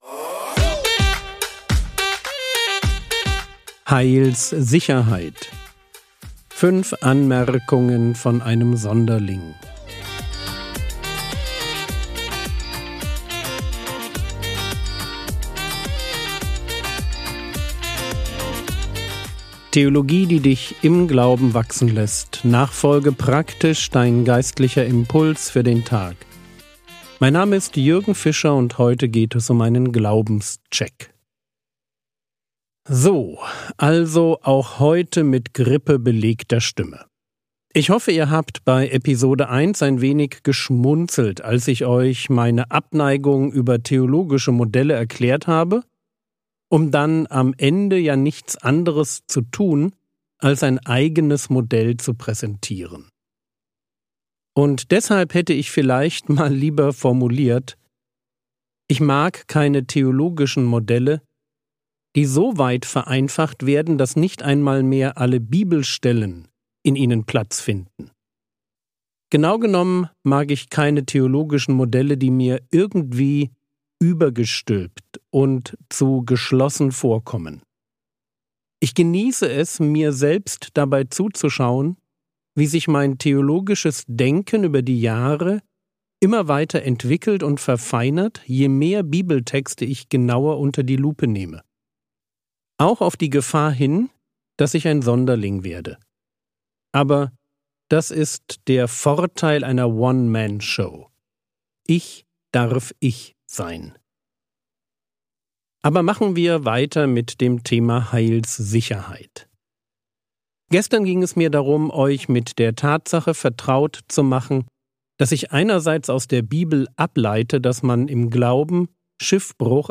Heilssicherheit (2/5) ~ Frogwords Mini-Predigt Podcast